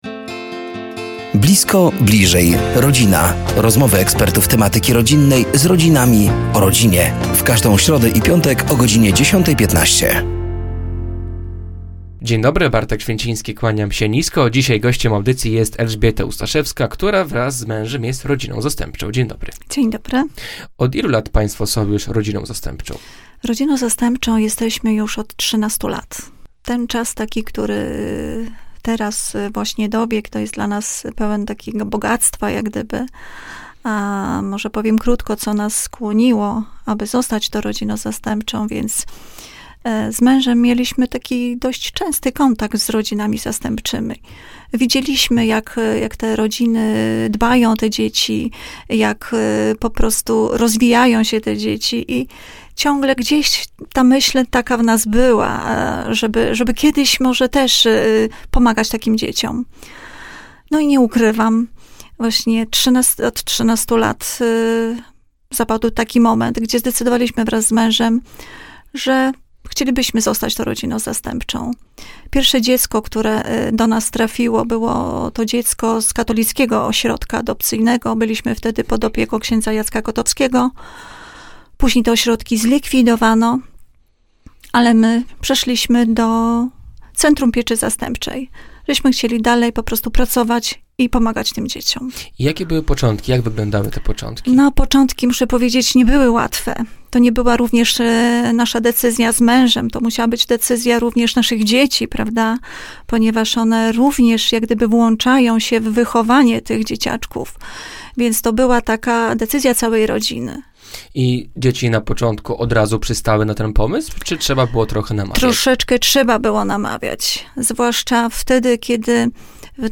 Blisko. Bliżej. Rodzina! To cykl audycji na antenie Radia Nadzieja. Do studia zaproszeni są eksperci w temacie rodziny i rodzicielstwa.